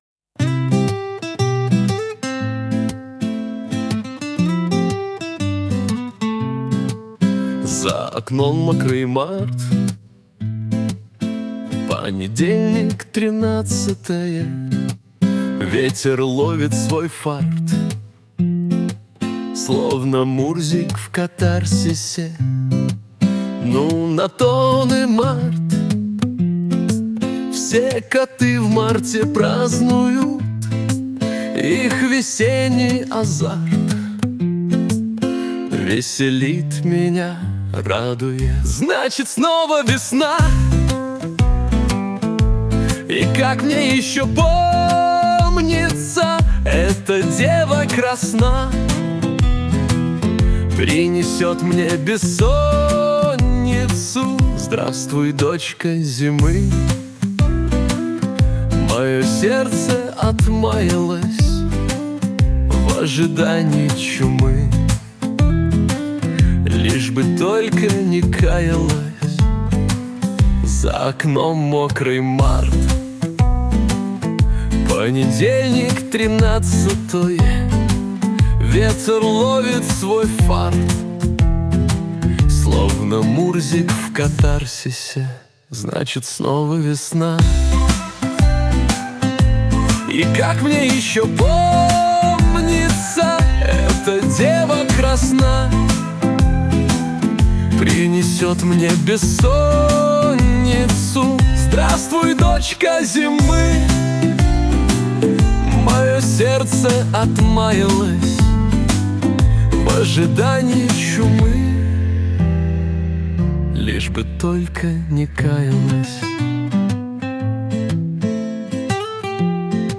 • Аранжировка: Ai
• Жанр: Поп